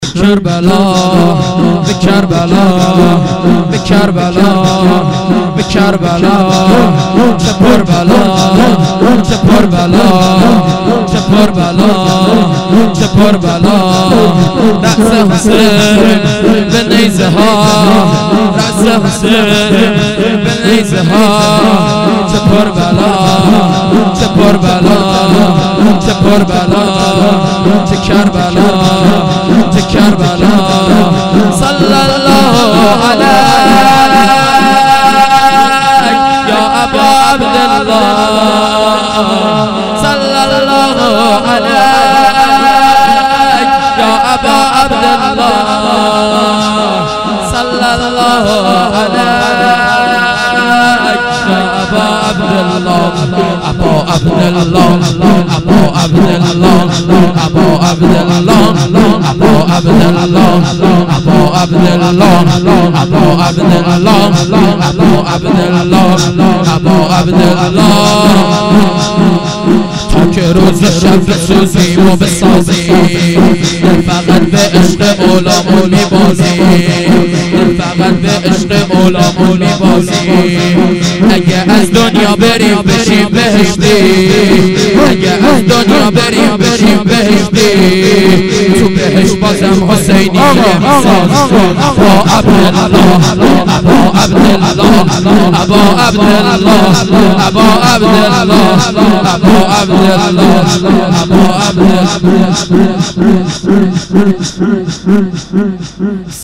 heiat-levaolabbassham-shahadat-hazrat-roghaieh-shor1.mp3